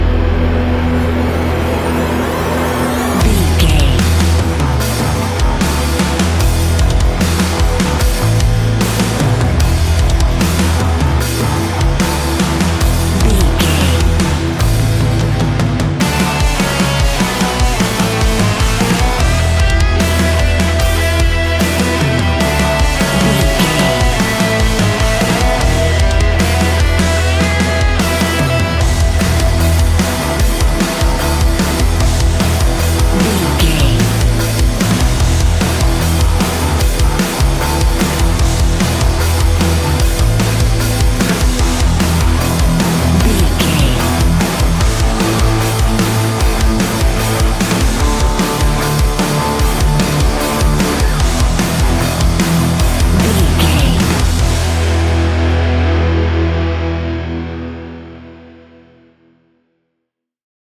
Ionian/Major
E♭
hard rock
heavy metal
instrumentals